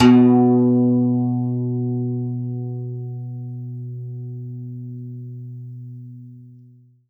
52-str04-zeng-b1.aif